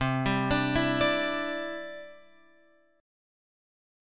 Cadd9 chord